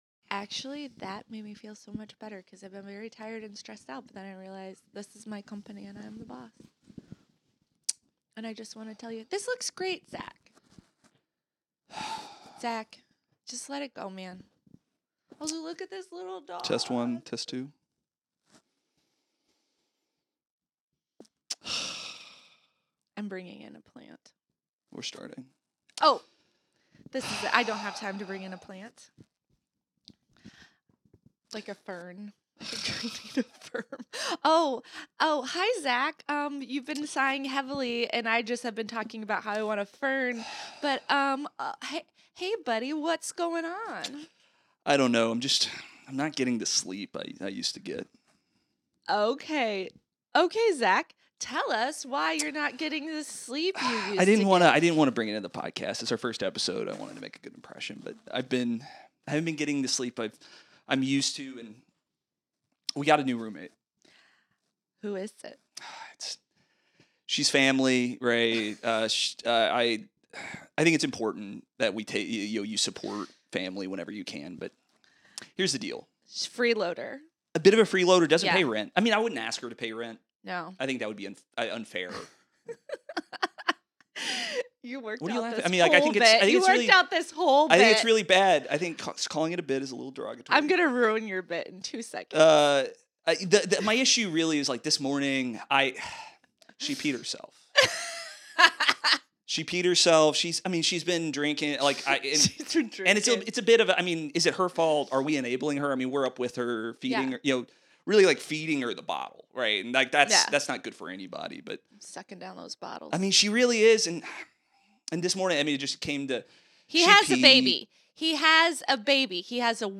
weekly comedy news podcast